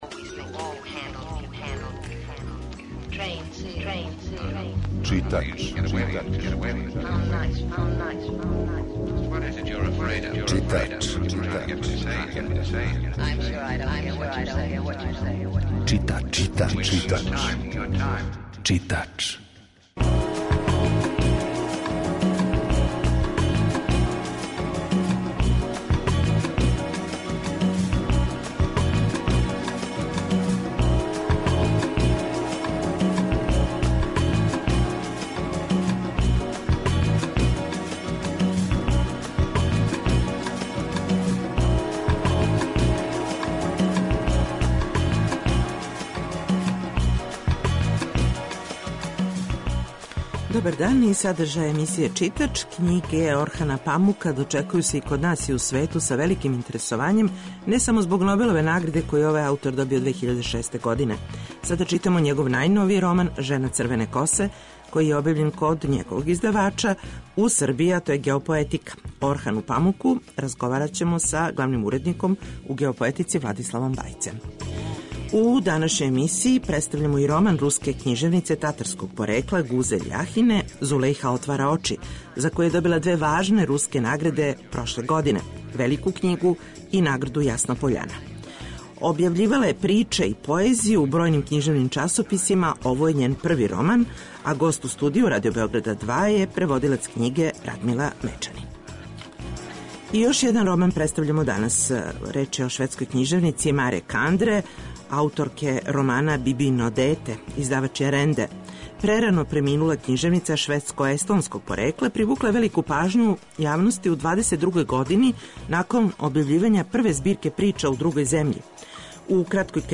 Емисија је колажног типа